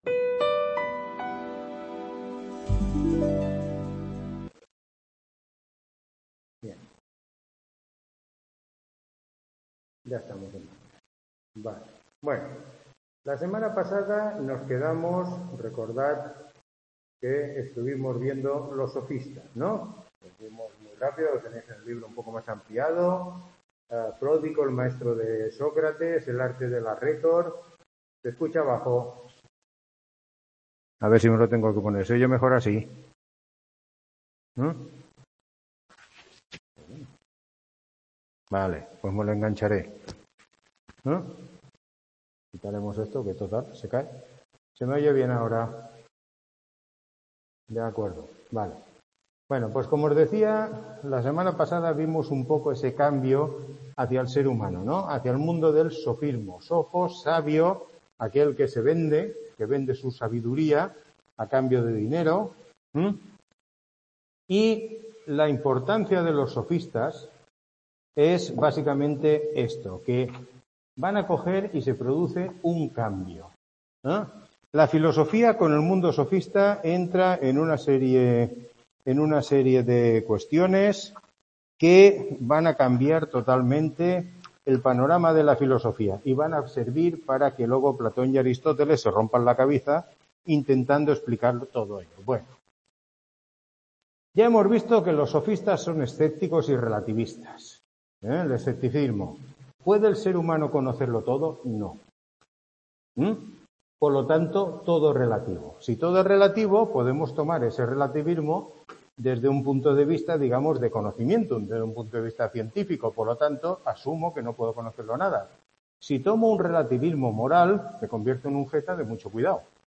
Tutoría 4